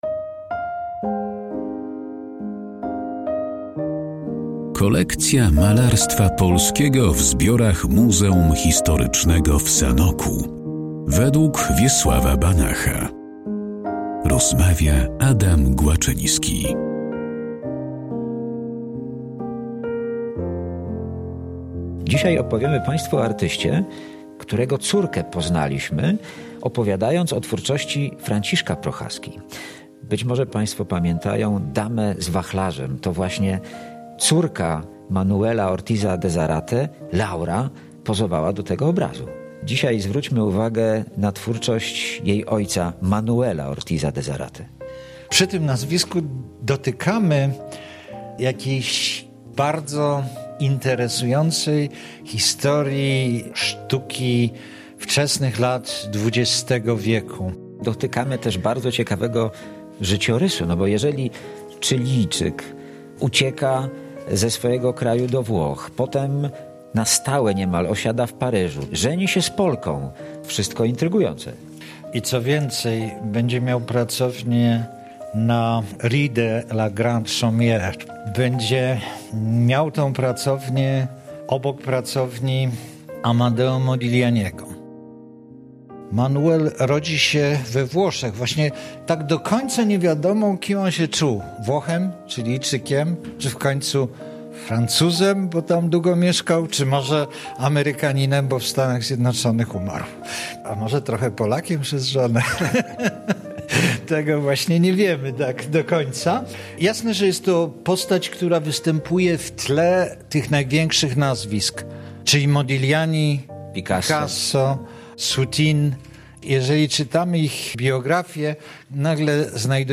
O twórczości Manuel Ortiz de Zárate Pinto i jego obrazach znajdujących się w Muzeum Historycznym w Sanoku rozmawiają: